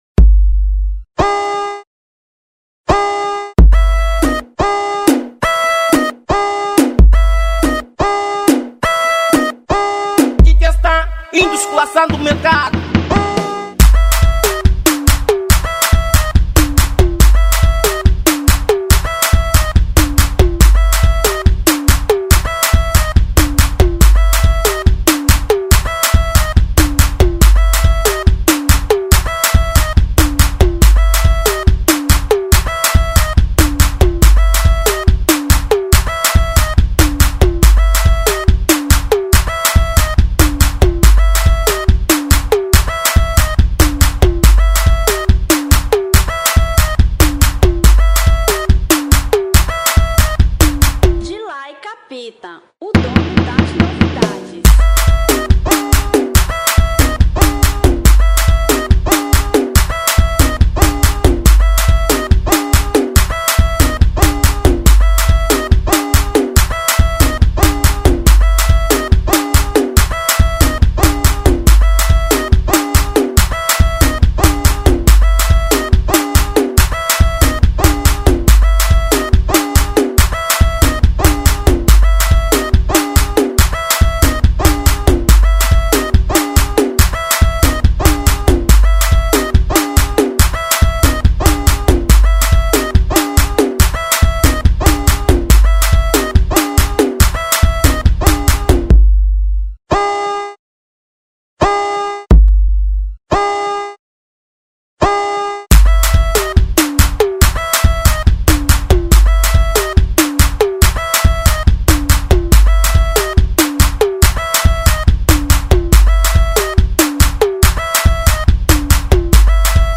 Instrumental 2009